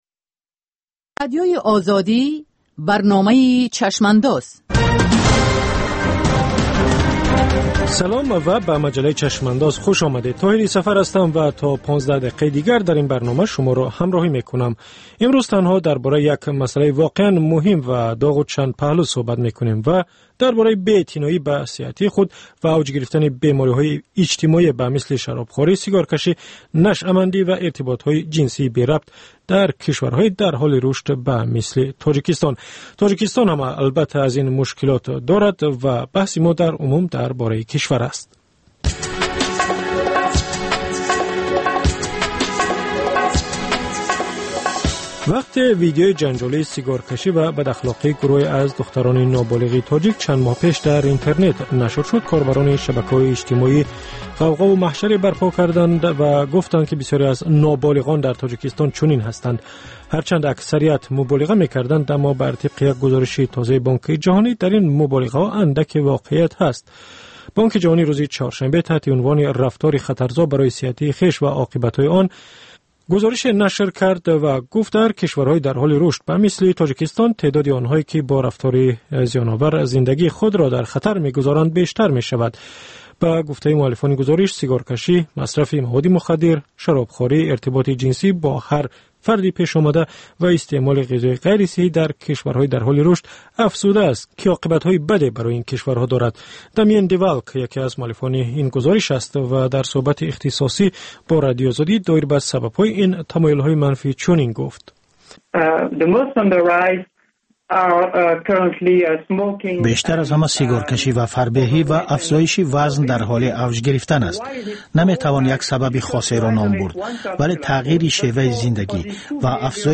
Ҷусторе дар рӯйдодҳои сиёсии ҷаҳон, минтақа ва Тоҷикистон дар як ҳафтаи гузашта. Мусоҳиба бо таҳлилгарони умури сиёсӣ.